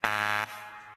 BuzzerBzzt.ogg